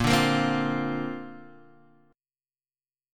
C7sus2sus4/A# chord